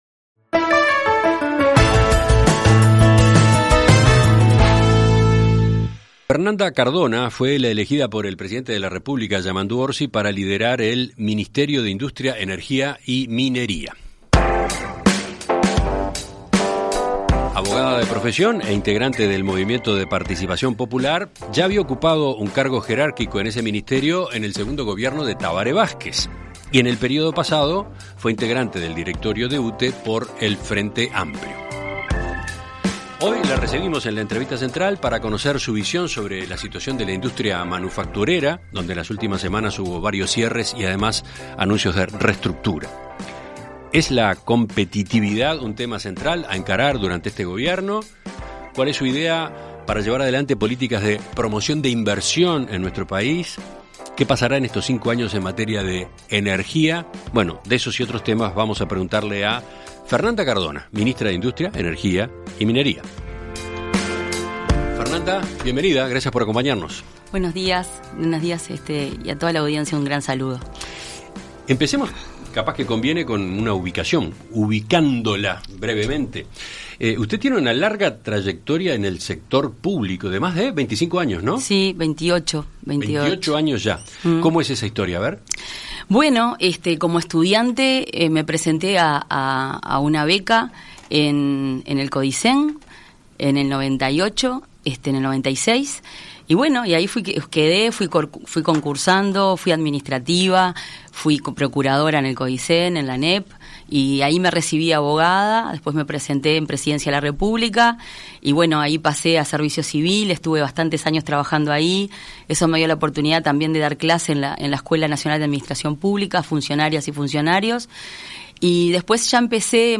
En Perspectiva Zona 1 – Entrevista Central: Fernanda Cardona - Océano
Conversamos con Fernanda Cardona , ministra del MIEM.